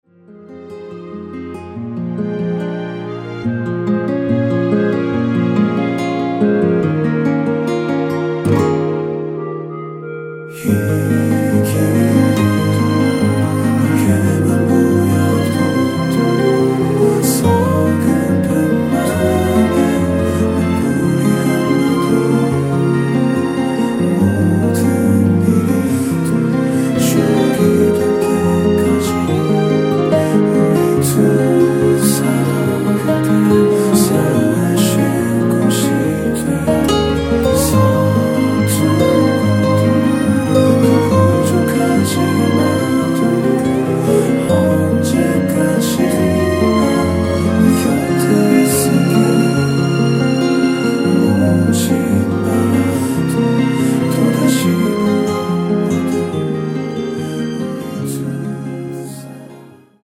원키에서(-1)내린 멜로디와 코러스 포함된 MR입니다.(미리듣기 확인)
Ab
앞부분30초, 뒷부분30초씩 편집해서 올려 드리고 있습니다.
중간에 음이 끈어지고 다시 나오는 이유는